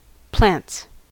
plants-us.mp3